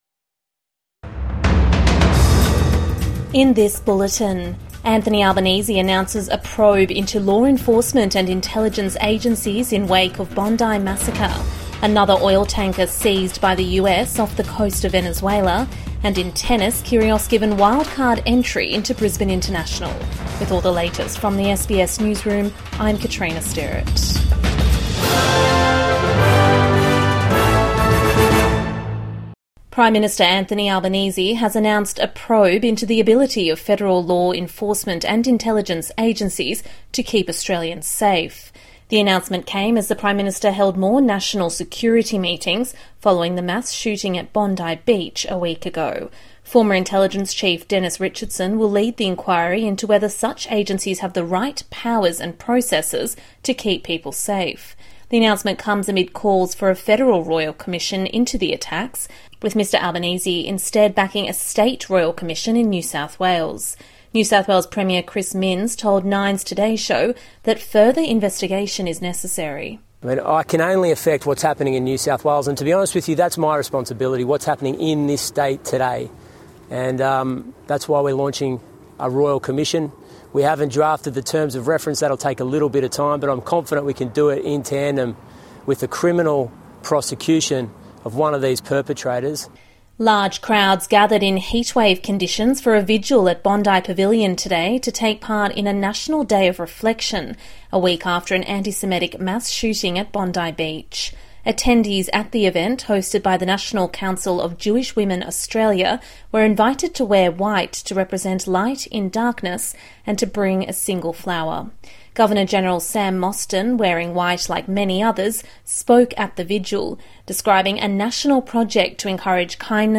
PM announces probe into law enforcement and intelligence agencies | Evening News Bulletin 21 December 2025